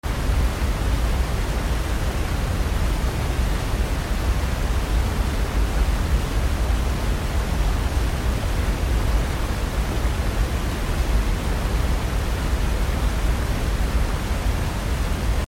Mp3 Sound Effect Rising up from crystal-clear waters, the view opens to a vibrant alpine meadow, crowned by the snow-capped peak of a majestic mountain in the heart of the Pacific Northwest. The shimmering creek reflects warm sunlight, while the breeze moves gently through the tall grasses, accompanied by the peaceful sound of birdsong—pure mountain serenity 😌 Rising Up From Crystal Clear Waters, Sound Effects Free Download.